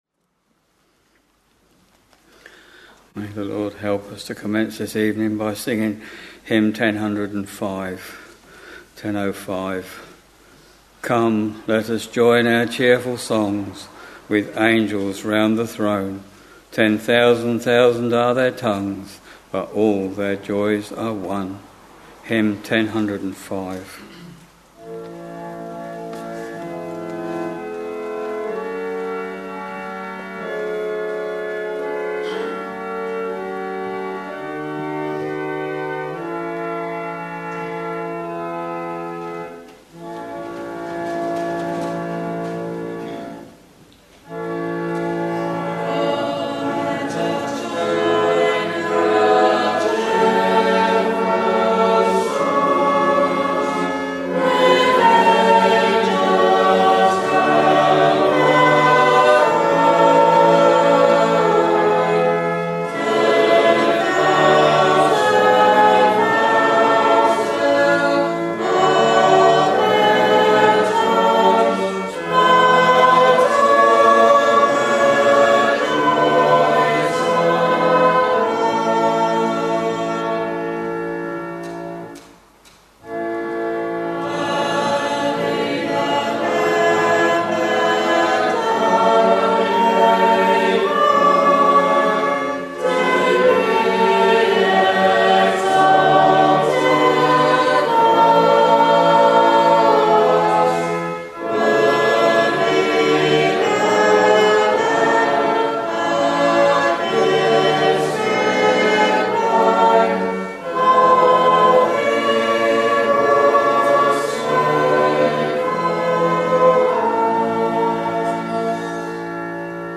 Week Evening Service Preacher
Hymns: 1005, 854, 211 Reading: S. of S. 5,6 Bible and hymn book details Listen Download File